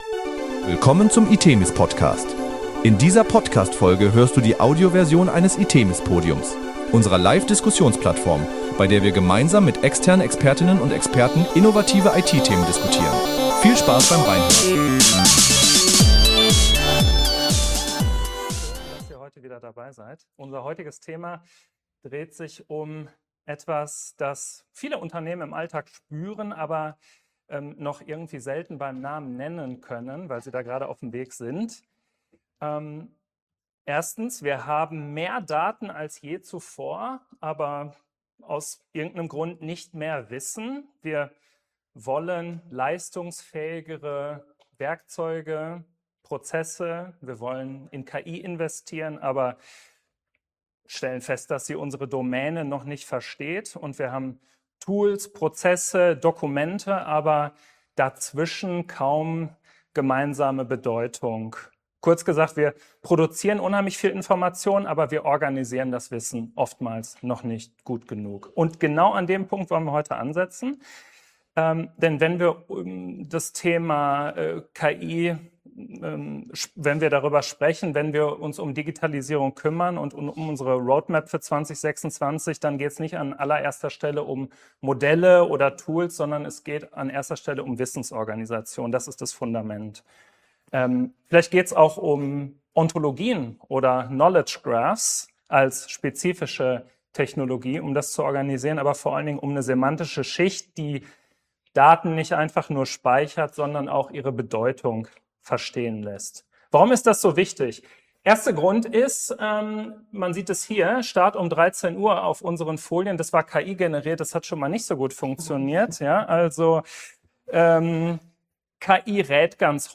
In dieser Folge des itemis PODCAST hört ihr die Audioversion eines itemis PODIUMS.